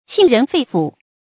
沁人肺腑 qìn rén fèi fǔ 成语解释 吸入清新空气或喝了可口饮料；像渗入内脏一样感到舒畅。
成语简拼 qrff 成语注音 ㄑㄧㄣˋ ㄖㄣˊ ㄈㄟˋ ㄈㄨˇ 常用程度 常用成语 感情色彩 中性成语 成语用法 动宾式；作谓语、定语；含褒义 成语结构 动宾式成语 产生年代 近代成语 成语正音 沁，不能读作“xīn”。